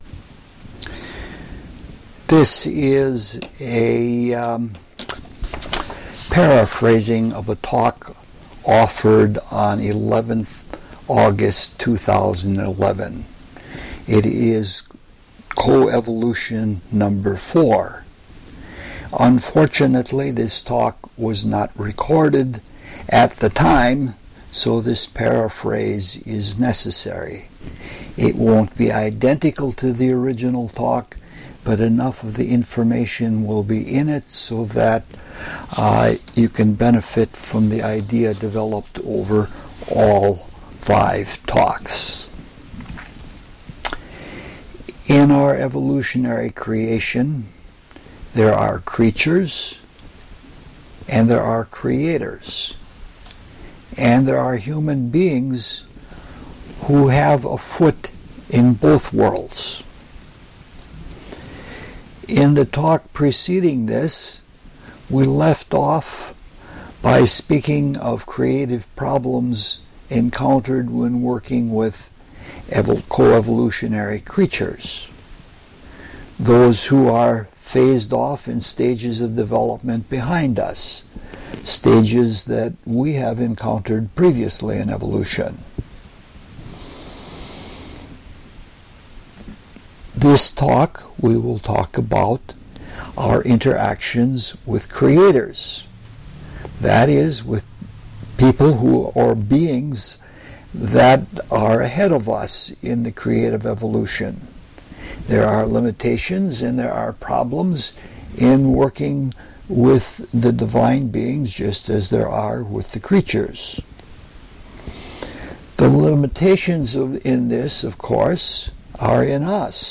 LECTURE SERIES